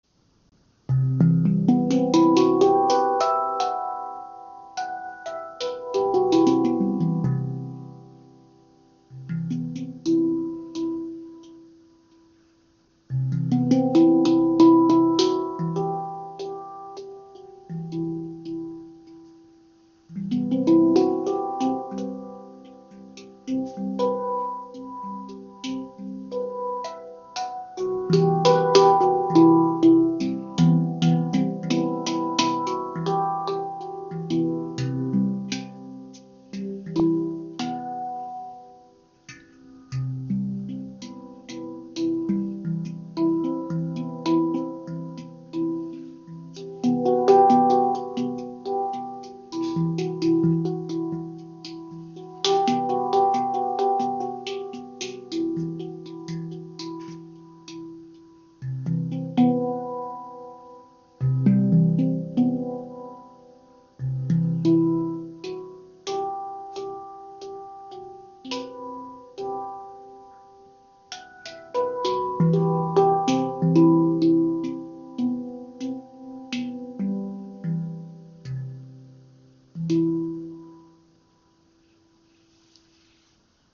C Aegean – Helle, inspirierende Klangstimmung für Meditation & Improvisation • Raven Spirit
Die C Aegean Stimmung verbindet Klarheit, Leichtigkeit und meditative Tiefe.
Klangbeispiel
Die ungarische Manufaktur MAG Instruments steht für hochwertige Handpans mit edler Oberfläche, warmem Klang und langem Sustain.